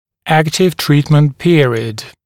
[‘æktɪv ‘triːtmənt ‘pɪərɪəd][‘эктив ‘три:тмэнт ‘пиэриэд]период активного лечения